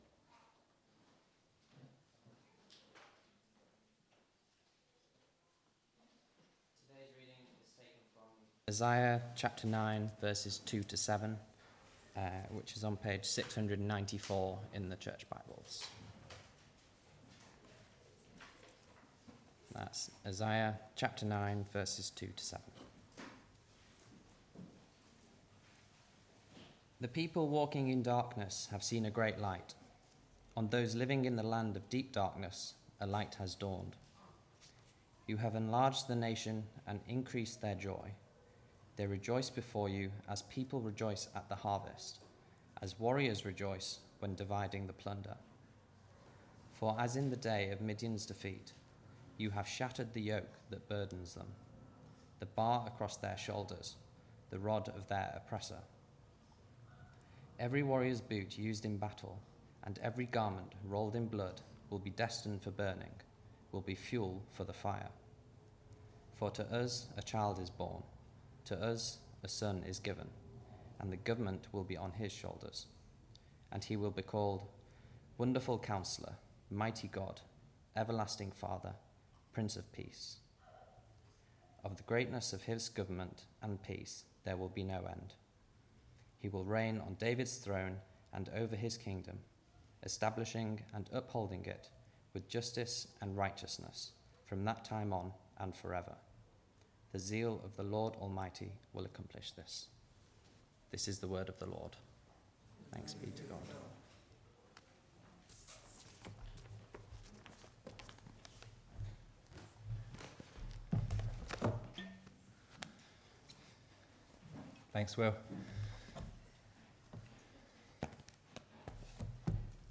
2-7 Service Type: Weekly Service at 4pm « Partners in Christ Christmas Carols
12th-Dec-Sermon.mp3